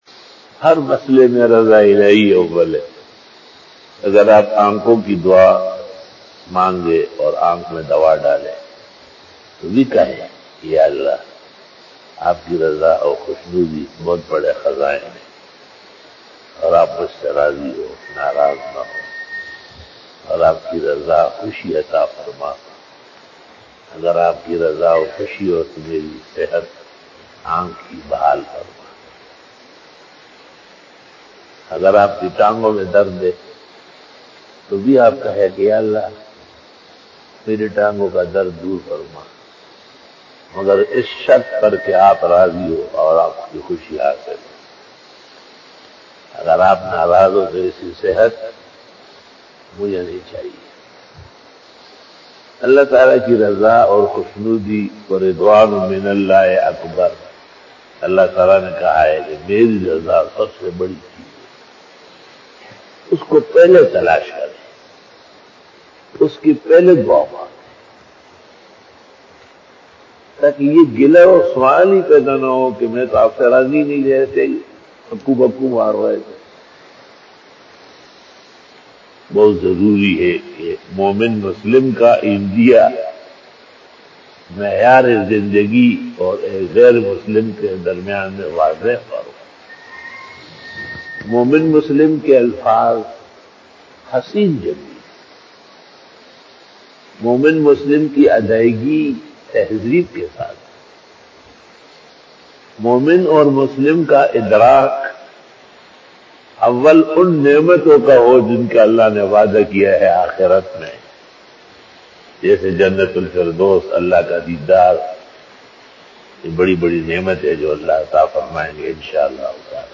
After Namaz Bayan
بیان بعد نماز فجر